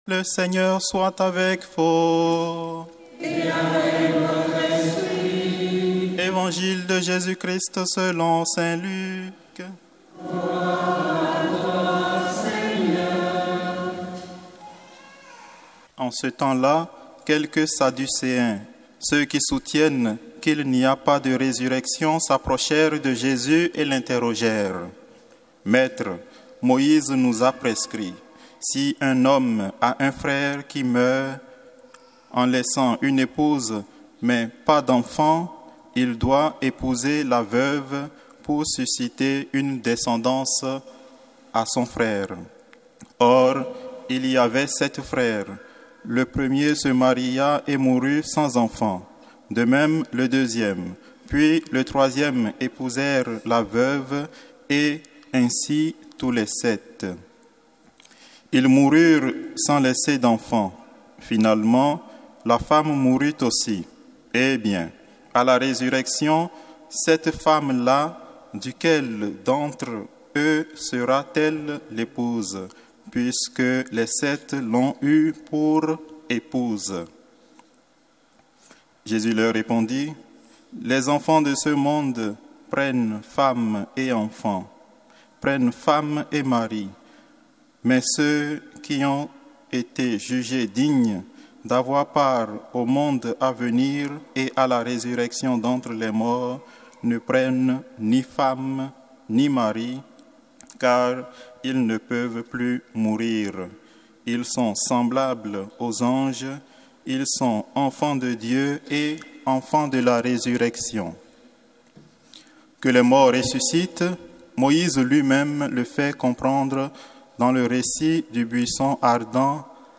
Évangile de Jésus Christ selon saint Luc avec l'homélie